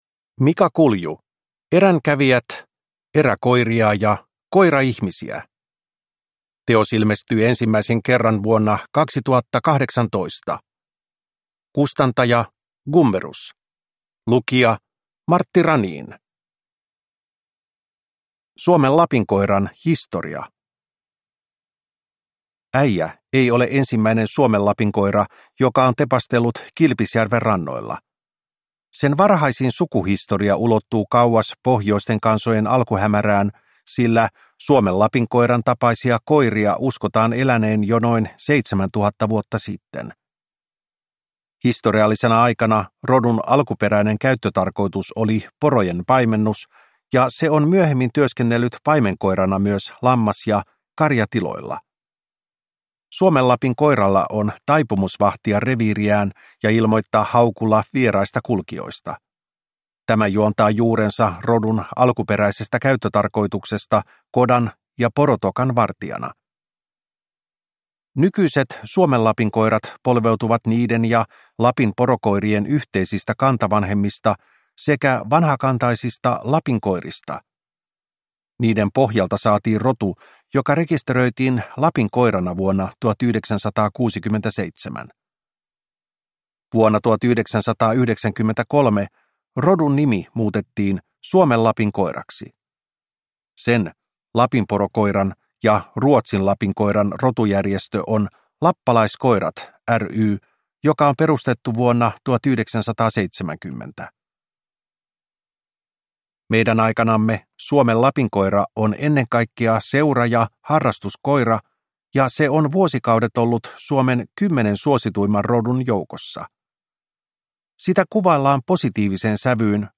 Eränkävijät – Ljudbok – Laddas ner